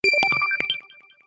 • Качество: Хорошее
• Категория: Рингтон на смс